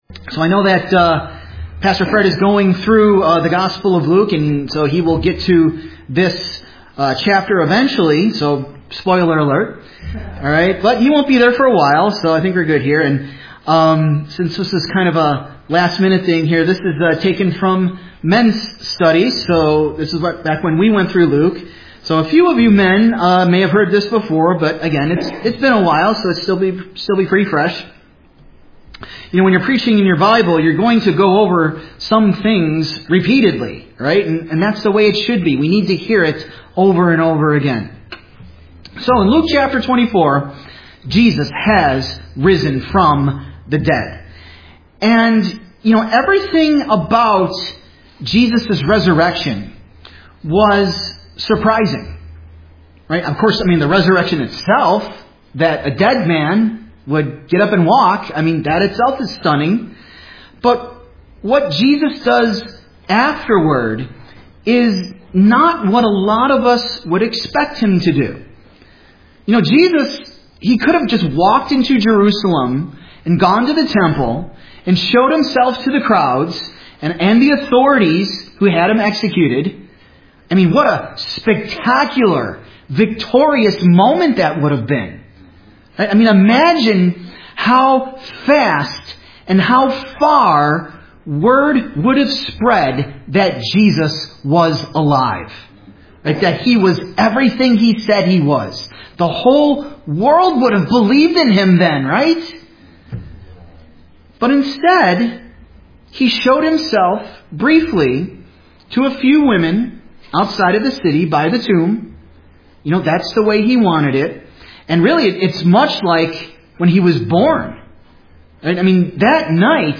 Sermons (audio)
Resurrection_Sunday_2026.mp3